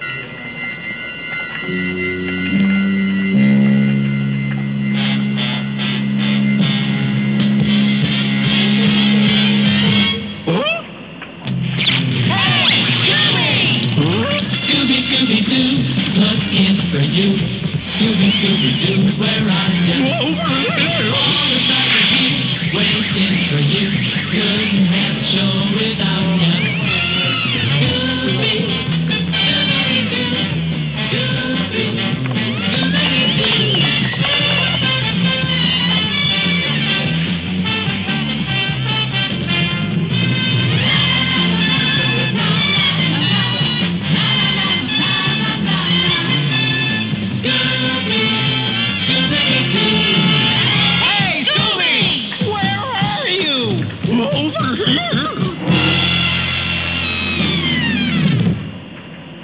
THEME SONGS